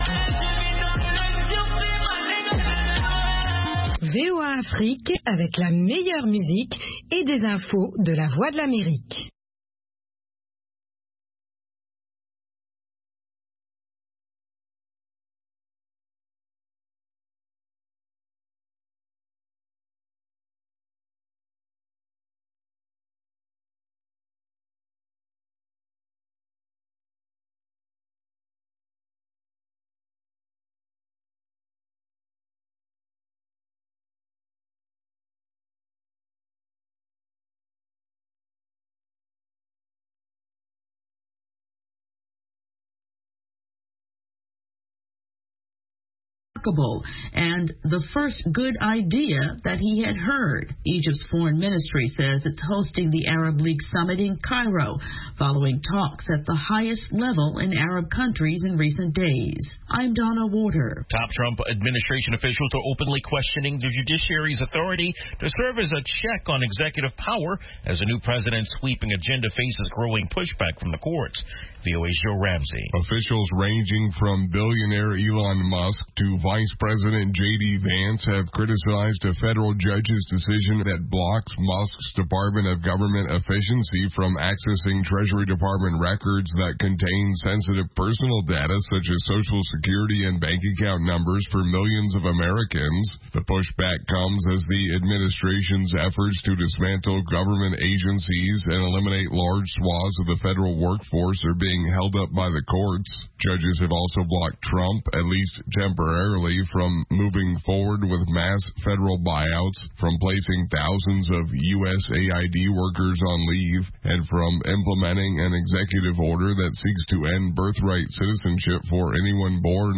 Bulletin d'information de 16 heures